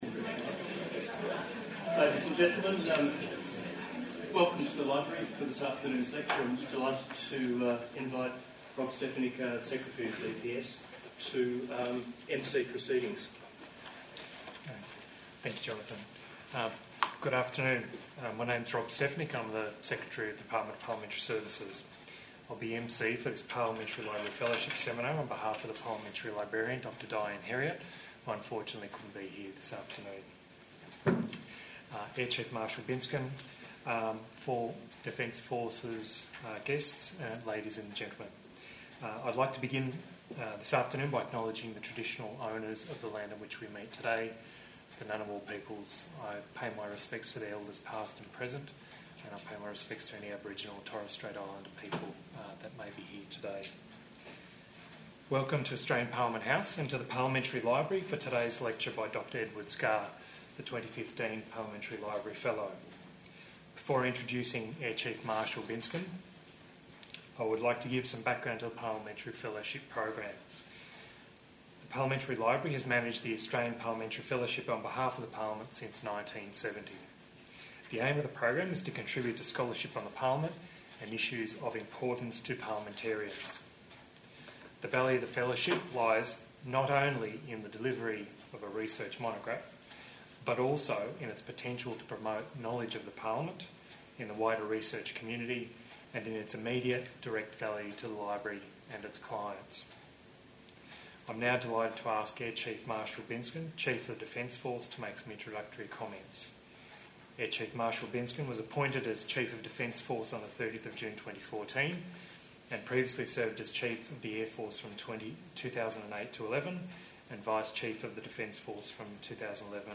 Seminars and Lectures 2016-17